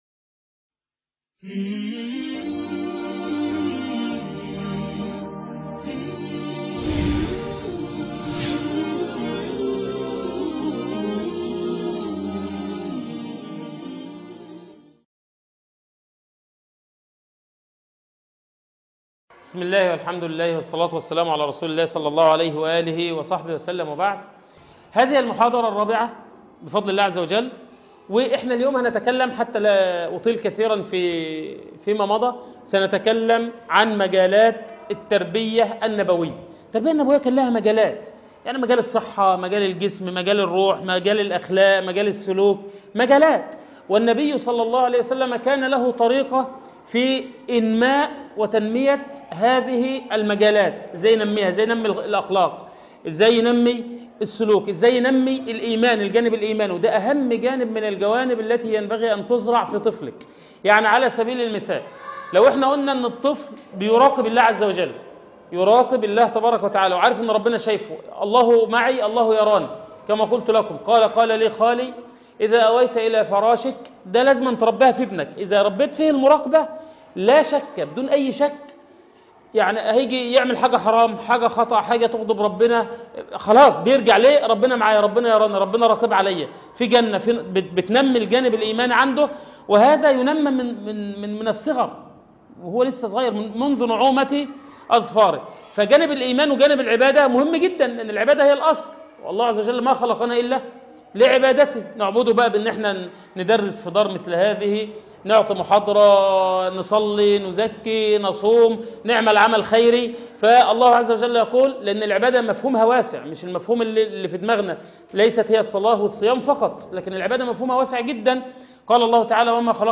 المحاضرة الرابعة - أصول التربية الإسلامية